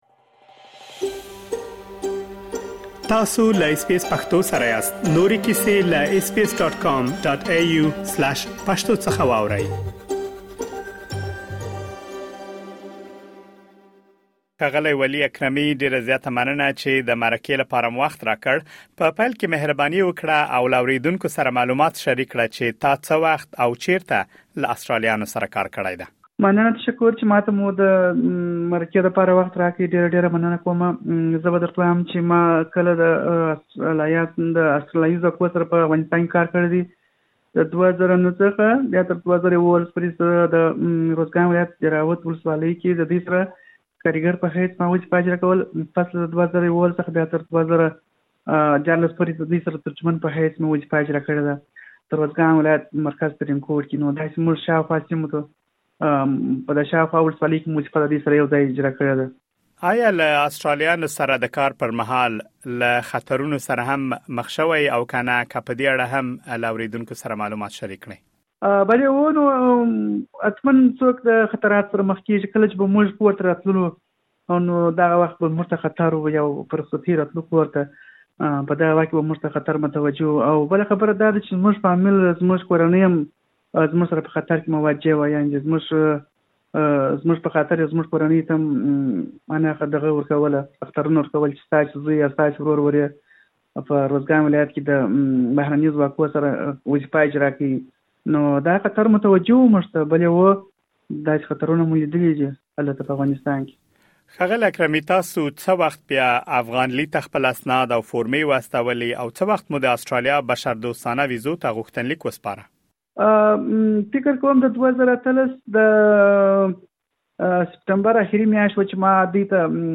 مهرباني وکړئ لا ډېر معلومات په ترسره شوې مرکې کې واورئ.